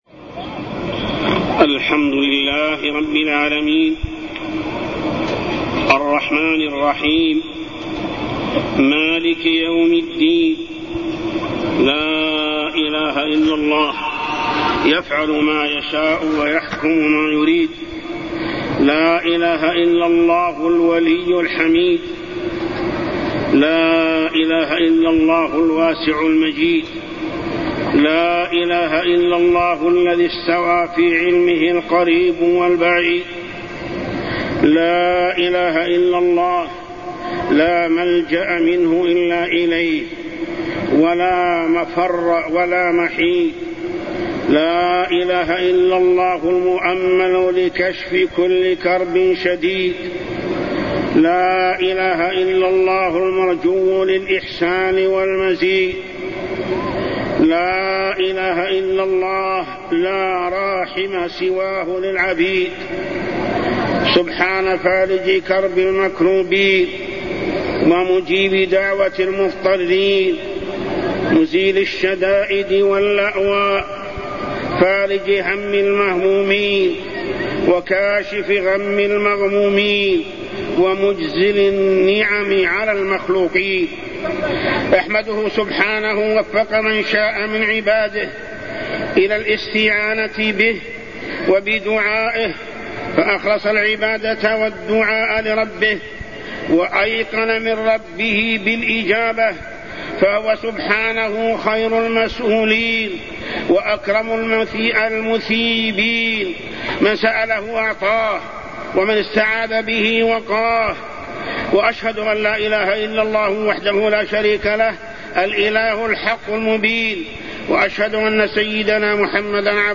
تاريخ النشر ١٣ رمضان ١٤٢٢ هـ المكان: المسجد الحرام الشيخ: محمد بن عبد الله السبيل محمد بن عبد الله السبيل الذنوب المعاصي من موانع القطر